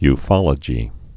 (y-fŏlə-jē)